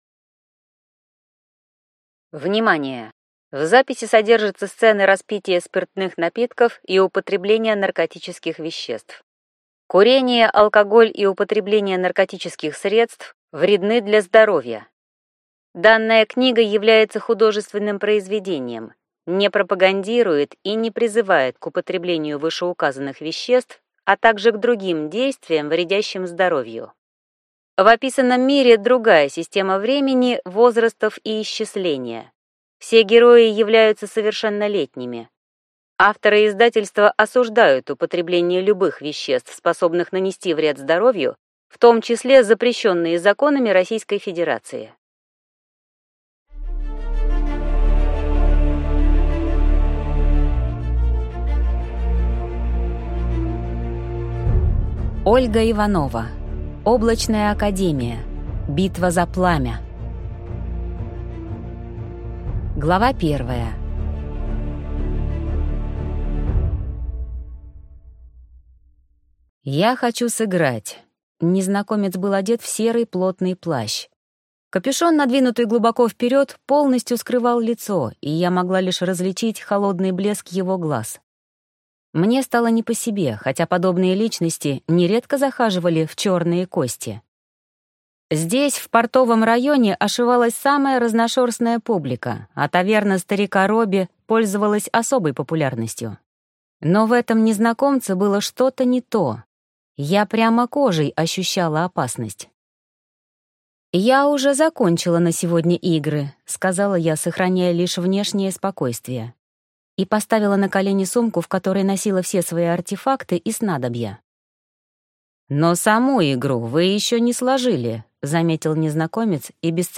Облачная академия. Битва за пламя (слушать аудиокнигу бесплатно) - автор Ольга Дмитриевна Иванова